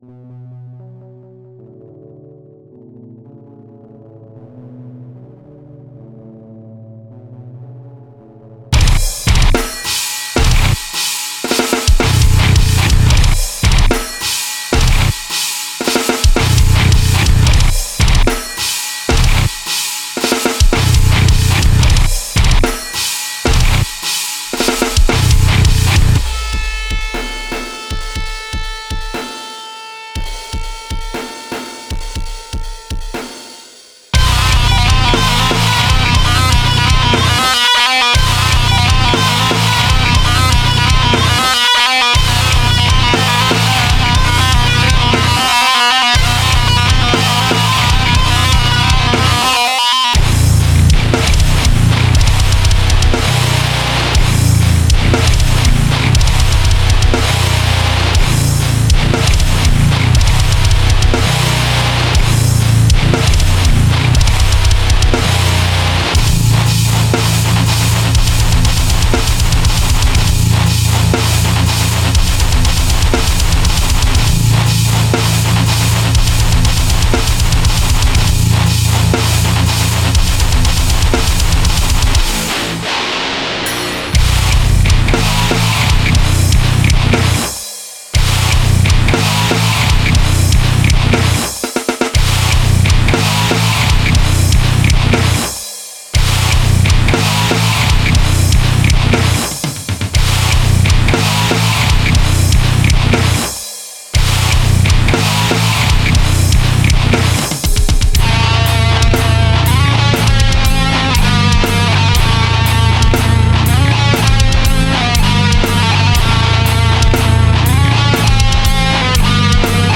Critique death metal song?
For now, it's just an instrumental.
The only piece of gear we had for guitars was a Digitech RP90 pedal, and the drums are arranged by MIDI with samples I gathered from various sources.
Considering this was done on a near-zero budget, how's it sound? I was going for a djent guitar tone, are there any tips on getting more of that metallic sound?